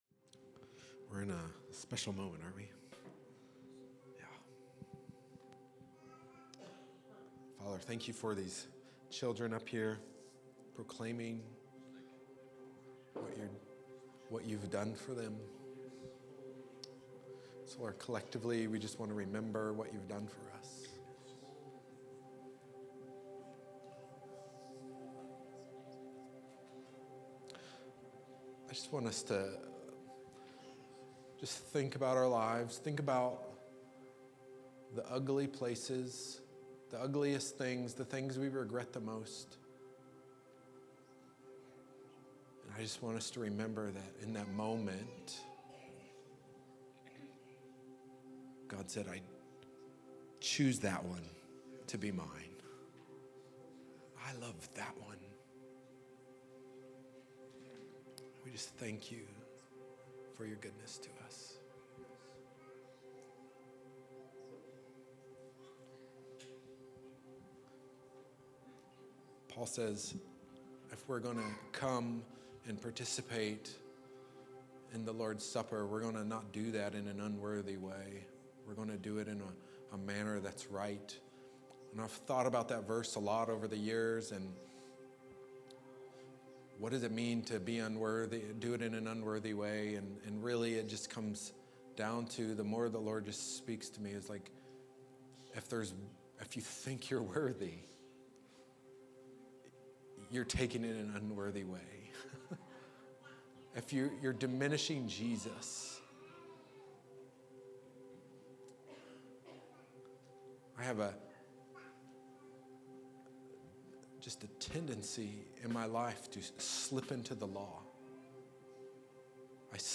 Communion Meditation